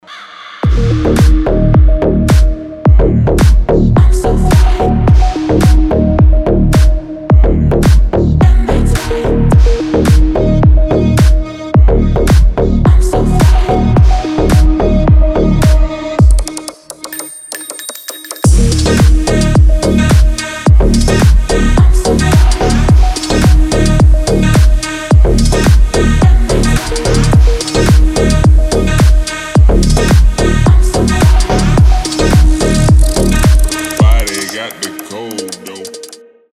• Качество: 320, Stereo
deep house
басы
G-House
Спокойные клубные басы